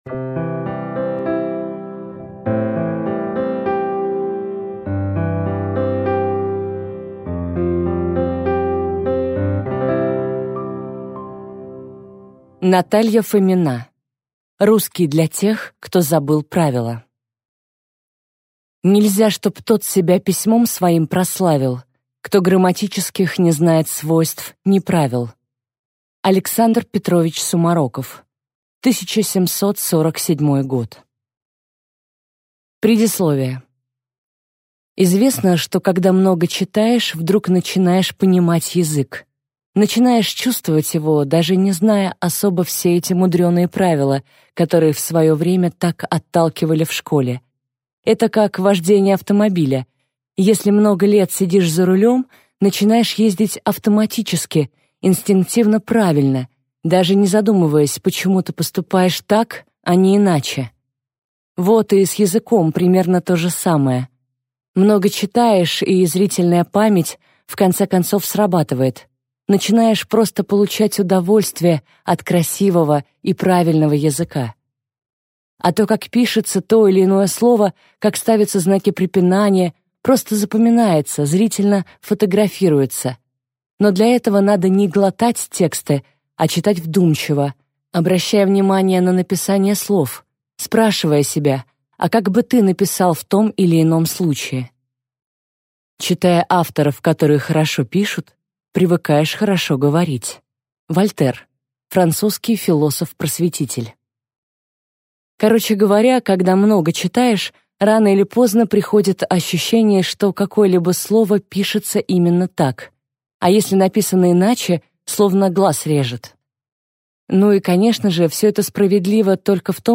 Аудиокнига Русский для тех, кто забыл правила | Библиотека аудиокниг